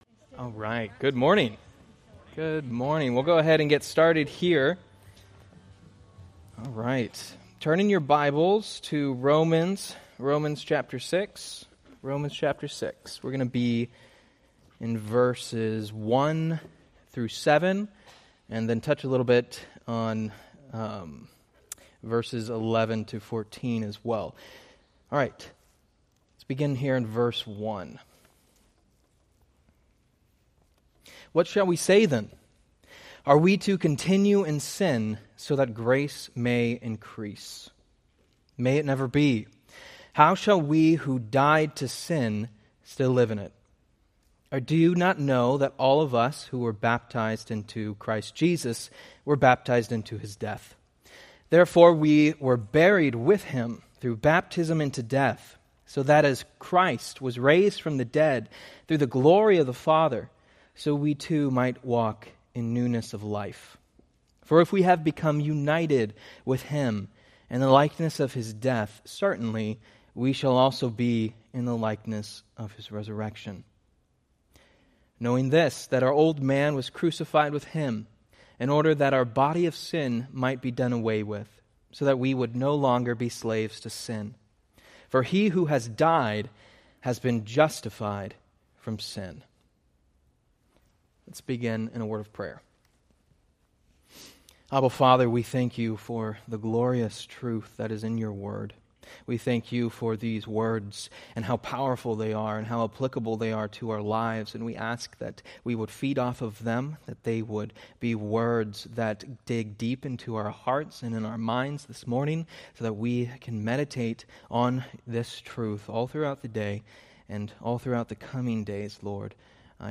Date: Oct 19, 2025 Series: Various Sunday School Grouping: Sunday School (Adult) More: Download MP3